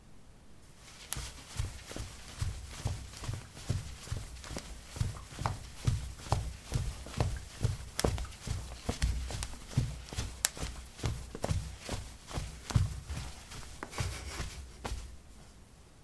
脚踏实地的瓷砖 " 陶瓷03A拖鞋行走
描述：在瓷砖上散步：拖鞋。在房子的浴室里用ZOOM H2记录，用Audacity标准化。